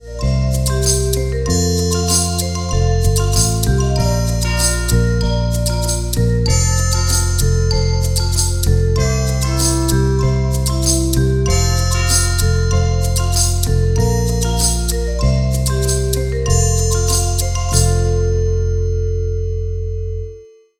Podkład do autorskiej piosenki pt.